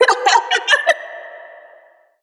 Metro Laughter 5.wav